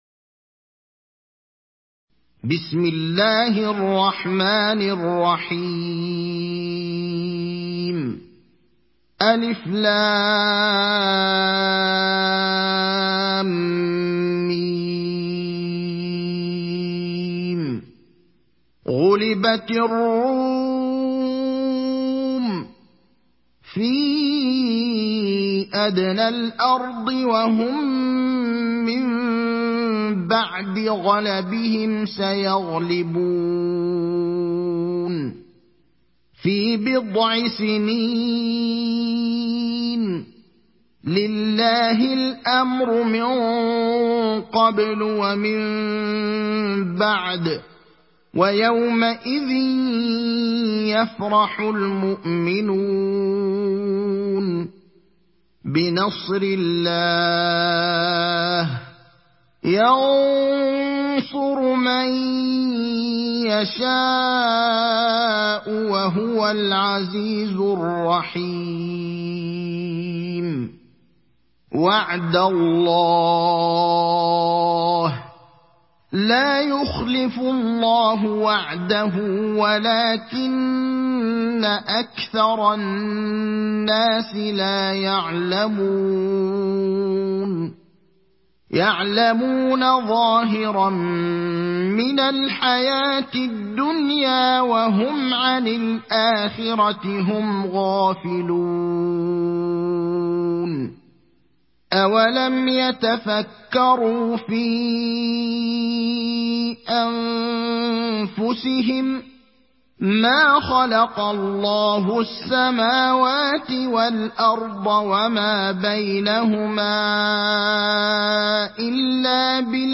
Sourate Ar Rum mp3 Télécharger Ibrahim Al Akhdar (Riwayat Hafs)